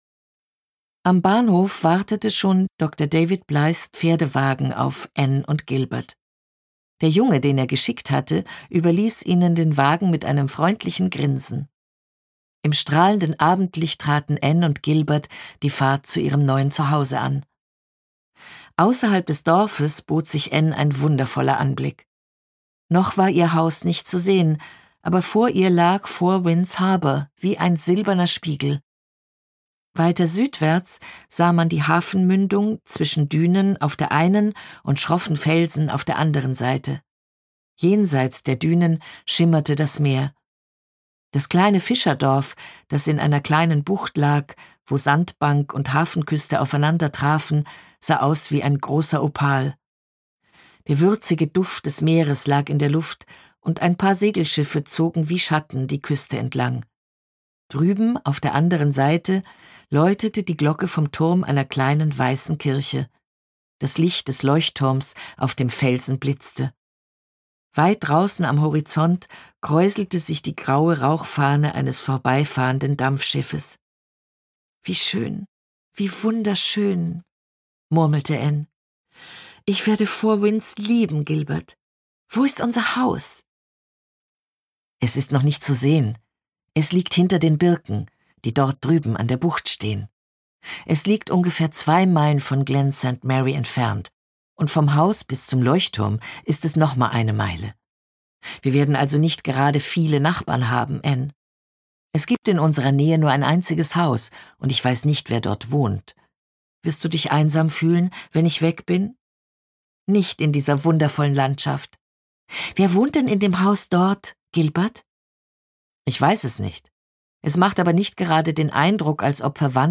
Wie schon bei ihren beliebten Lesungen der Jane-Austen-Romane entführt sie mit warmem Timbre in eine unvergessene Welt.
Gekürzt Autorisierte, d.h. von Autor:innen und / oder Verlagen freigegebene, bearbeitete Fassung.
Anne in Four Winds Gelesen von: Eva Mattes